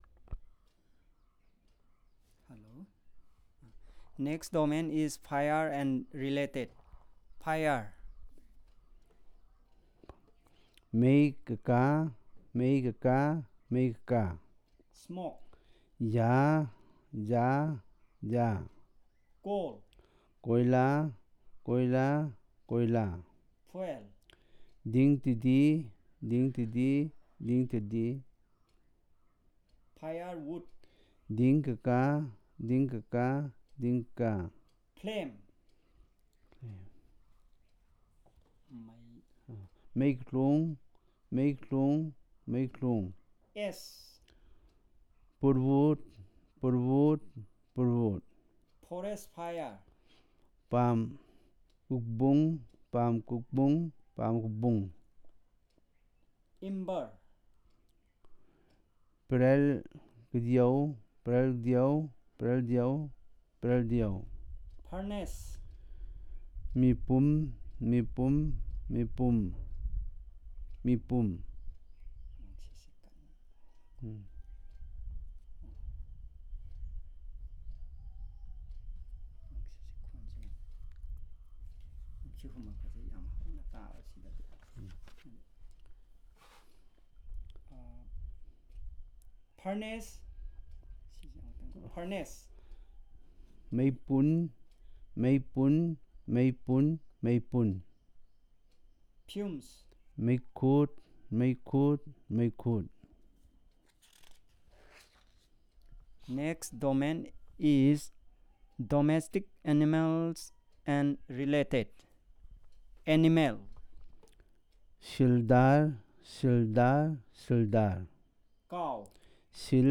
Elicitation of words about fire and its related terms